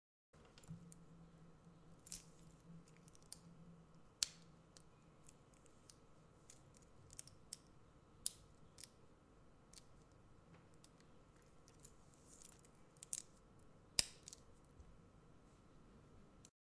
На этой странице собраны разнообразные звуки застежек и фермуаров от женских кошельков, сумок и других аксессуаров.
Звук перекрестной защелки кошелька или сумочки с парой бусин